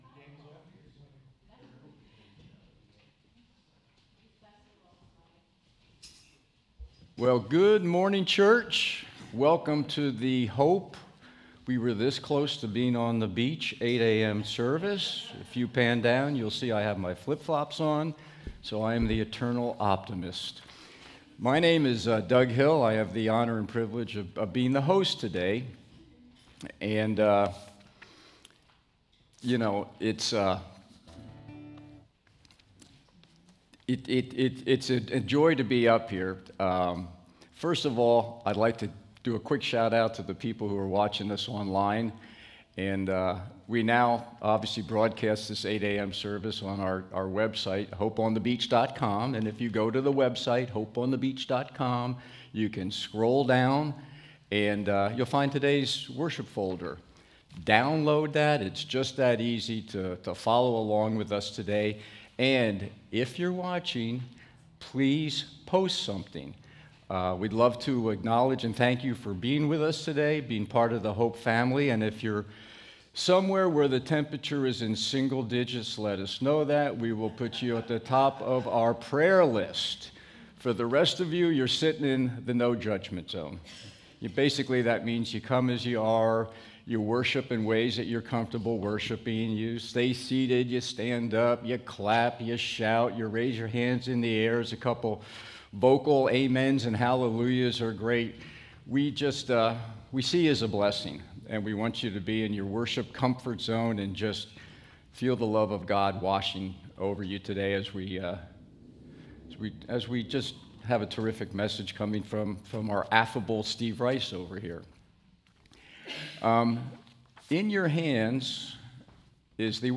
SERMON DESCRIPTION This message from Hosea 1–2 reveals a God who speaks through lived sacrifice.